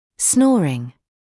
[‘snɔːrɪŋ][‘сноːрин]храп; храпение